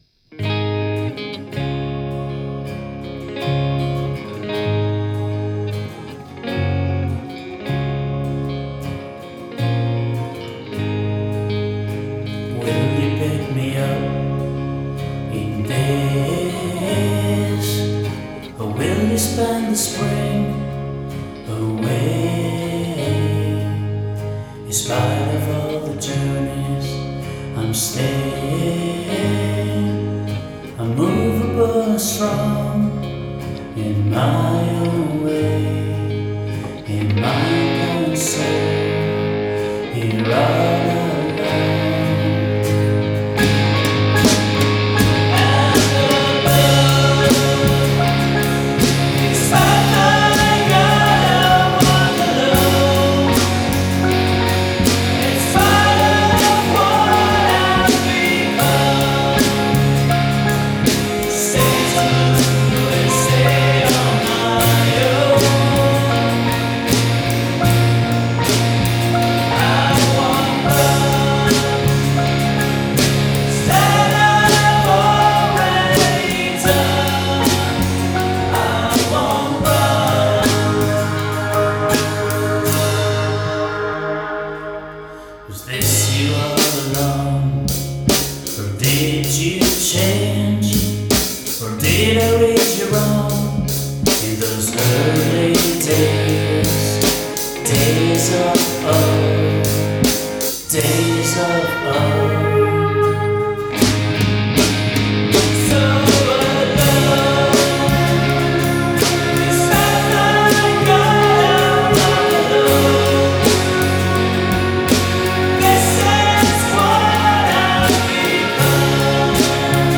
vocals, guitars, bass, keyboards, drums